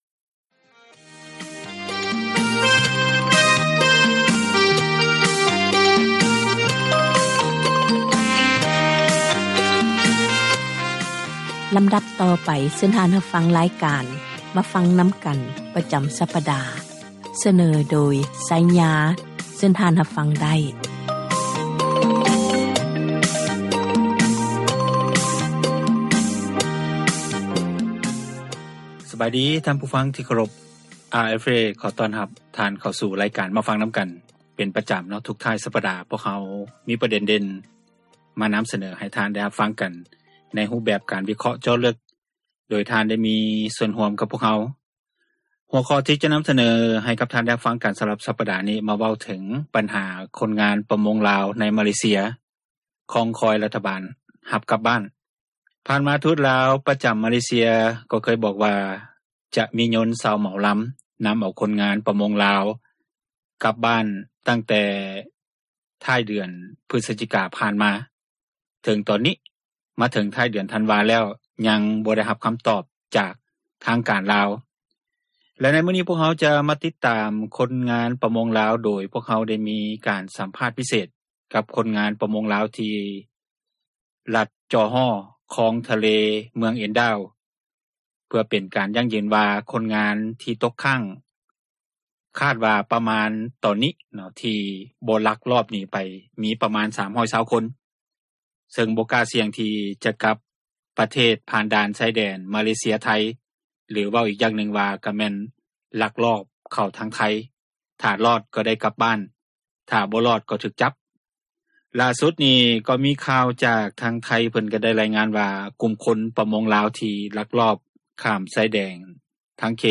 ການສົນທະນາ ໃນຫົວຂໍ້ ບັນຫາ ແລະ ຜົລກະທົບ ຢູ່ ປະເທດລາວ